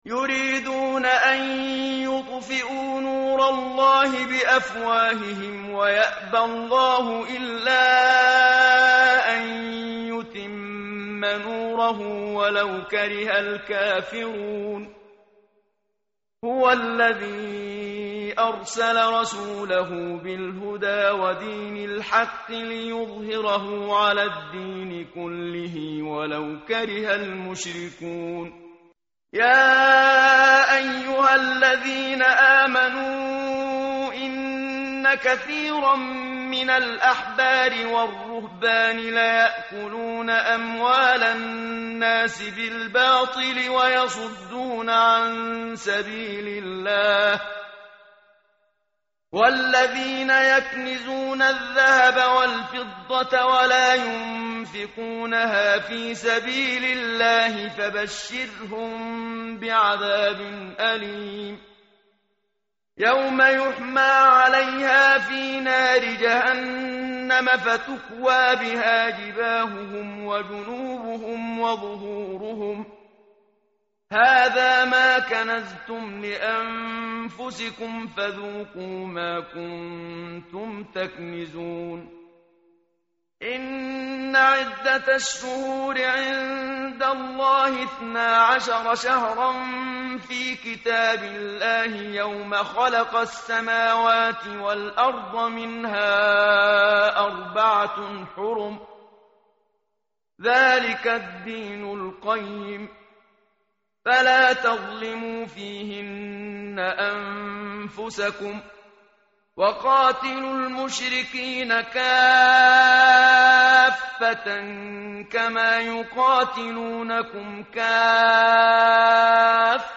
متن قرآن همراه باتلاوت قرآن و ترجمه
tartil_menshavi_page_192.mp3